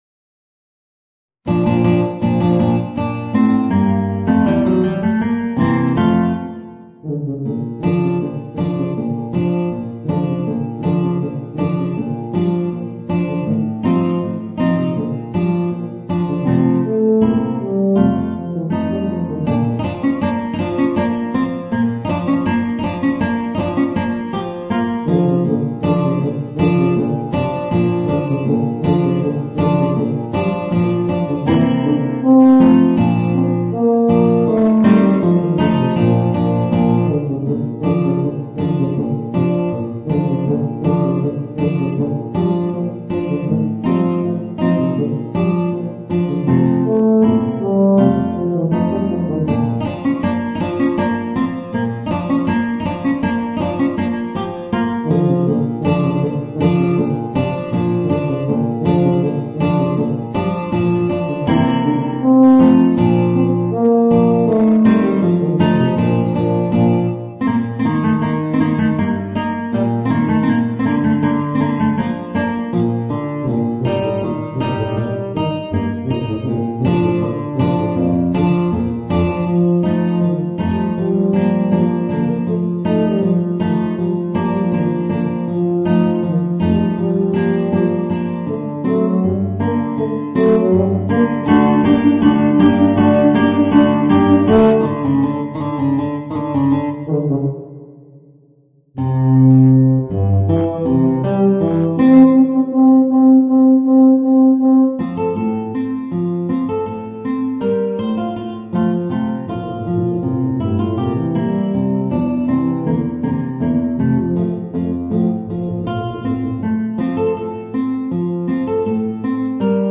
Voicing: Guitar and Eb Bass